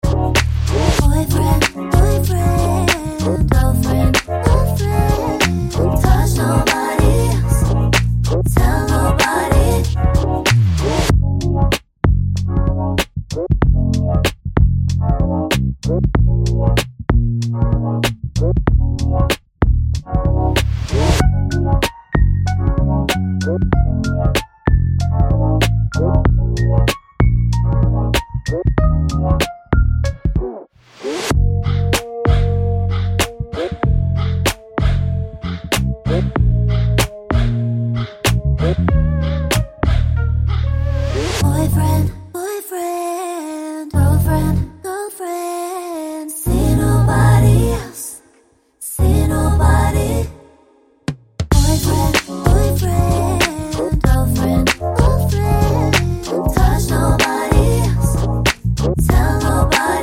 no Backing Vocals R'n'B / Hip Hop 3:06 Buy £1.50